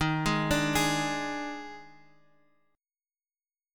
EbM7sus4 chord